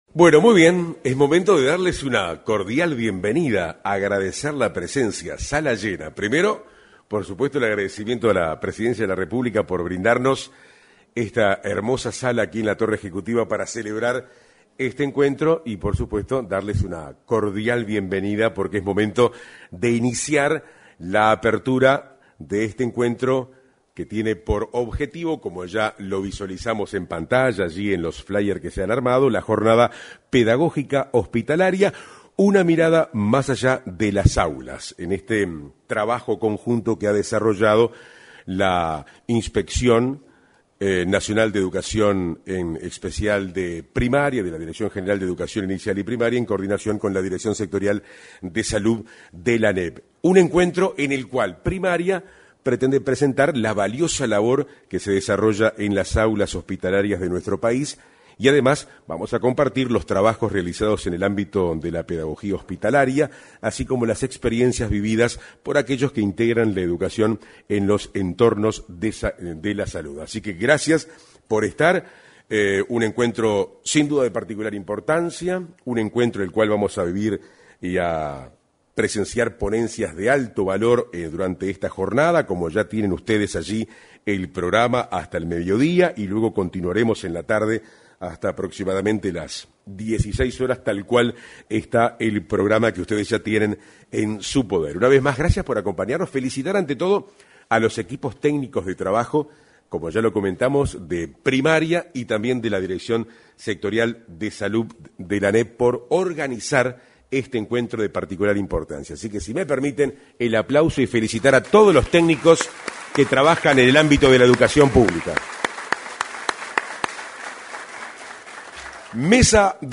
Jornada de pedagogía hospitalaria 22/11/2024 Compartir Facebook X Copiar enlace WhatsApp LinkedIn Este viernes 22, se realizó, en el salón de actos de la Torre Ejecutiva, la actividad Pedagogía Hospitalaria: Una Mirada más allá de las Aulas. En el acto de apertura, se expresaron la directora sectorial de Salud de la Administración Nacional de Educación Pública (ANEP), Patricia Odella; el subdirector general de Educación Inicial y Primaria, Eduardo García Teske, y la consejera de la ANEP Dora Graziano.